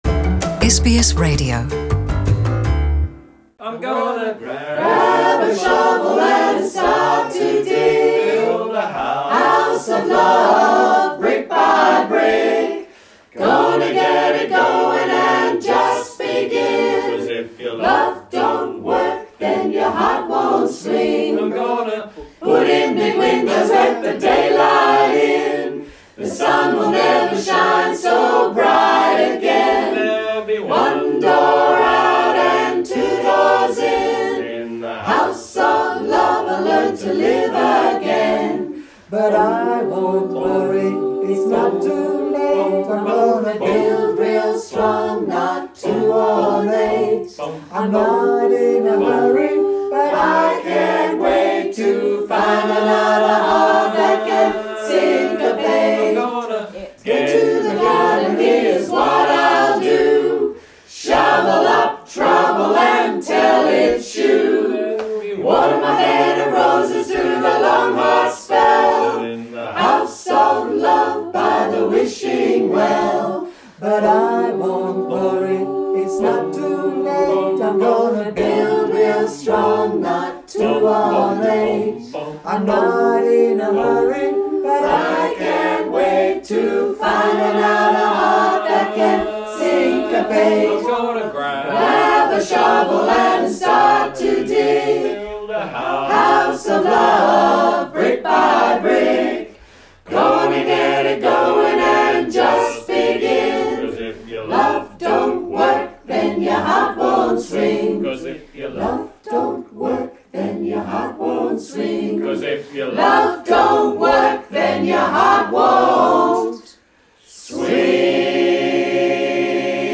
Acapella koor